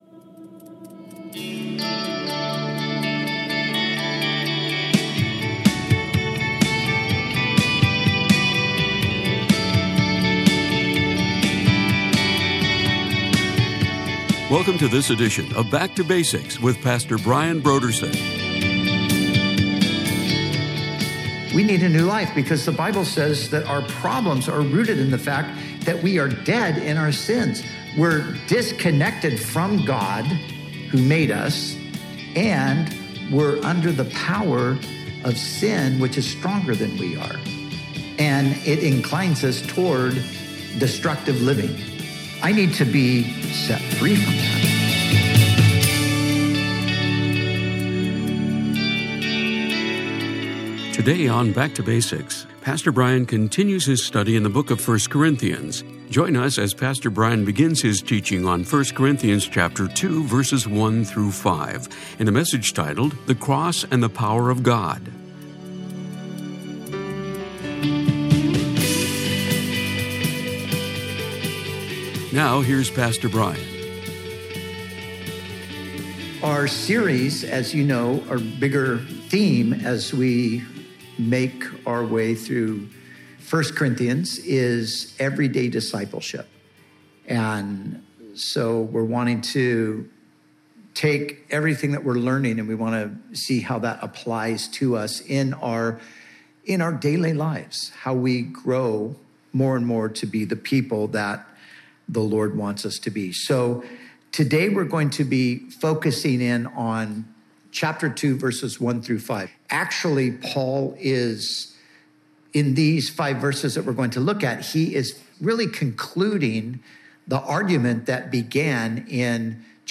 Bible teaching